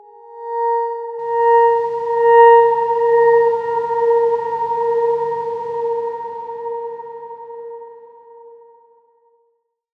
X_Darkswarm-A#4-f.wav